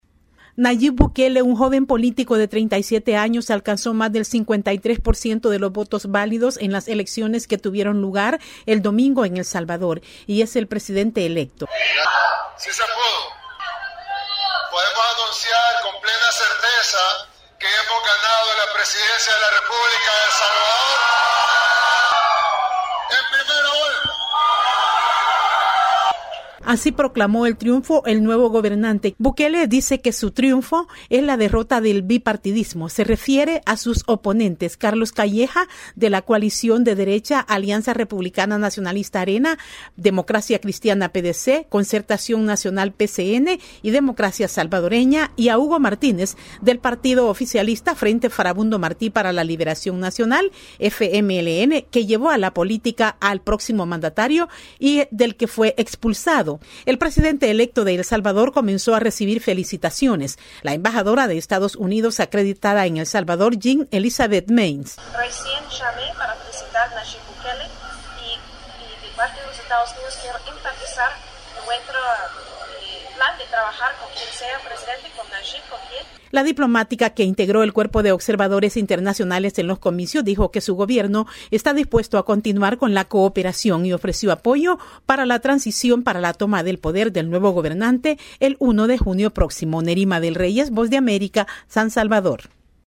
VOA: Informe desde El Salvador